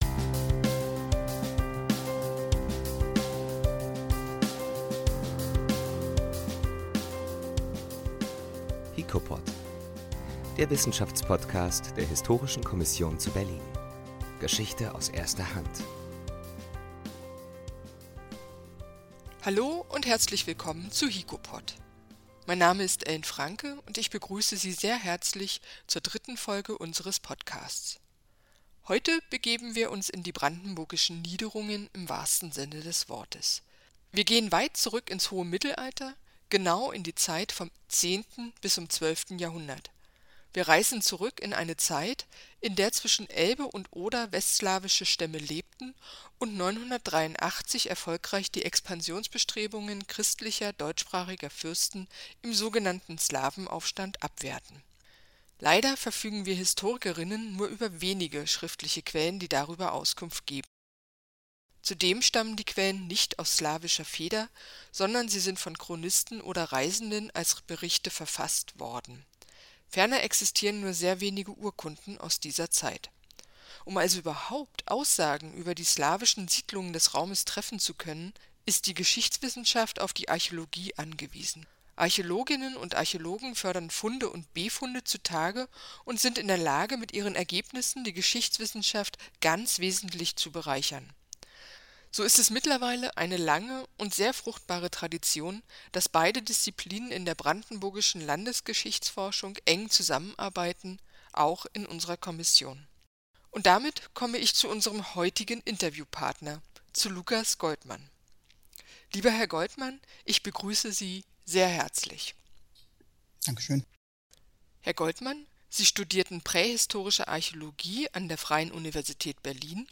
3 I HiKoPod I Inselsiedlungen im westslawischen Raum – Ein Interview